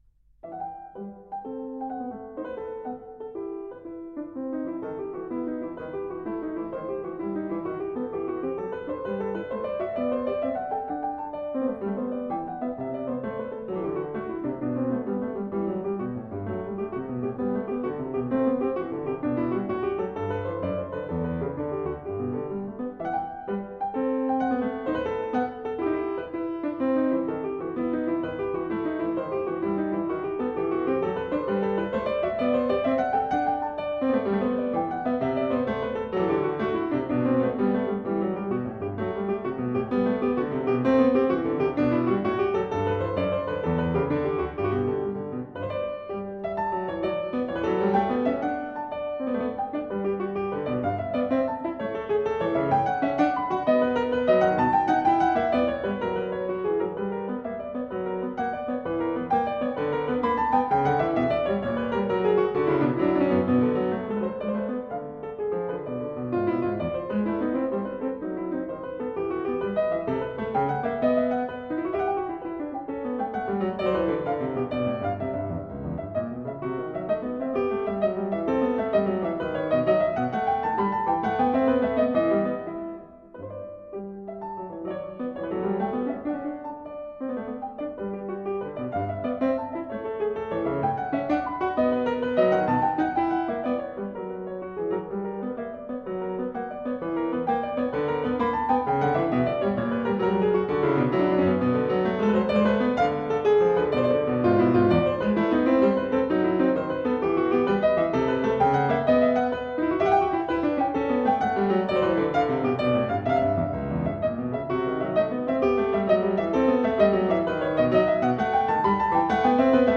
e-moll